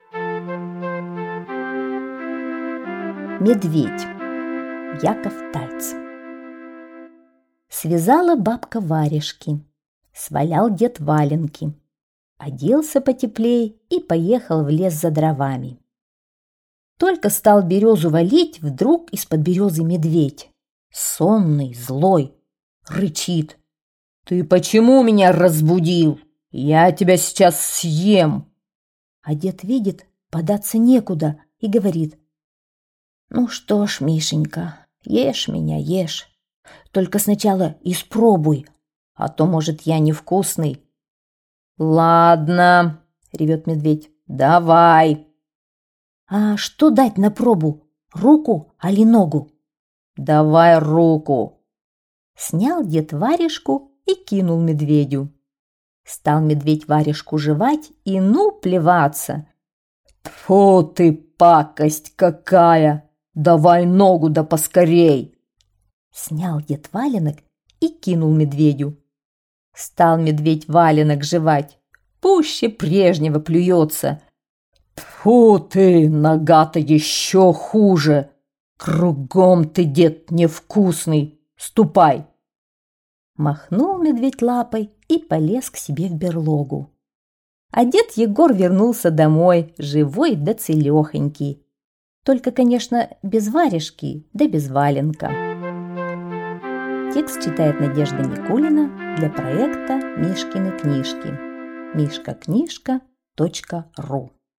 Медведь - аудиосказка Тайца Я. Пошел дед Егор в лес за дровами и случайно разбудил спящего медведя.